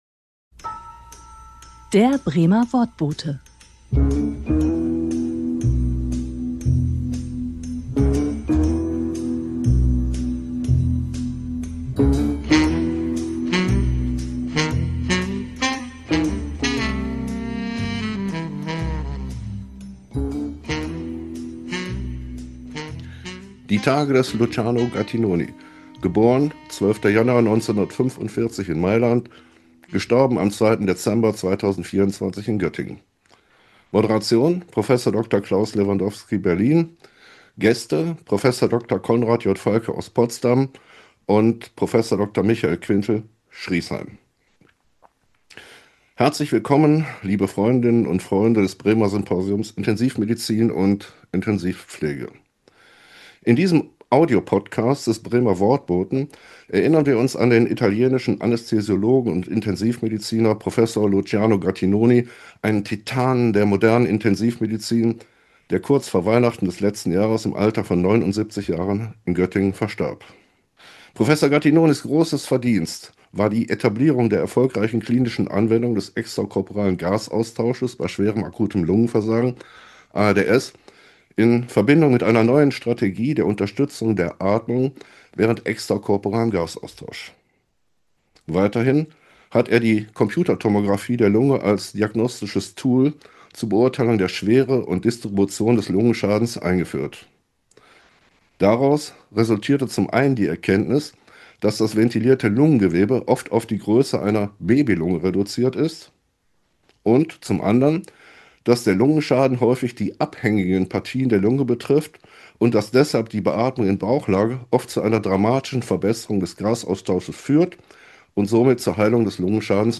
Meine Gäste beim Podcast sind Zeitzeugen.